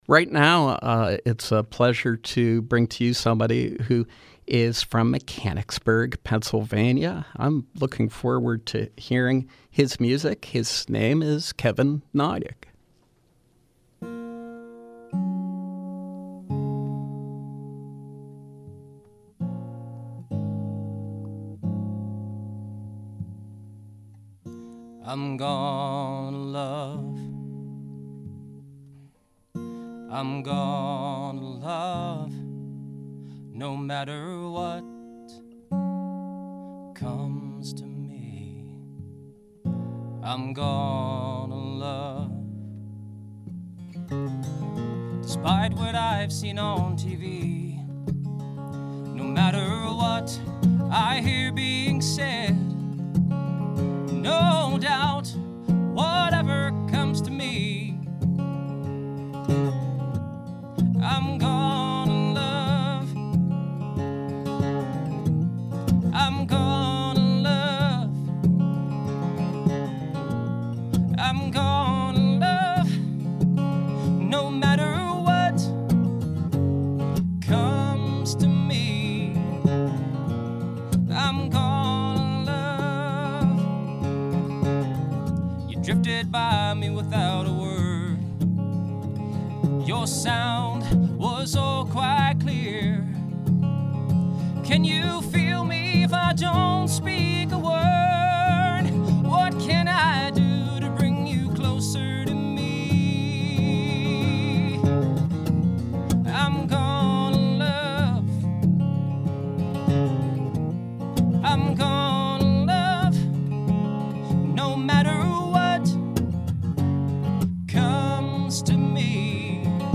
Live performance
Interviews